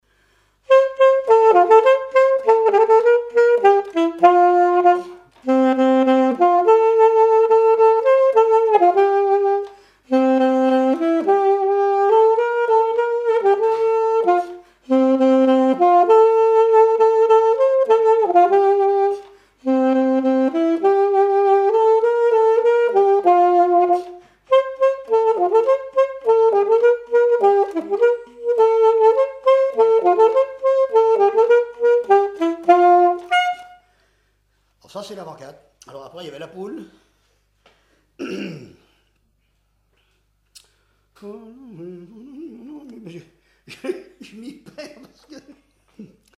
Mémoires et Patrimoines vivants - RaddO est une base de données d'archives iconographiques et sonores.
danse : avant-quatre
témoignages et instrumentaux
Pièce musicale inédite